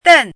“扽”读音
dèn
扽字注音：ㄉㄣˋ
国际音标：tən˥˧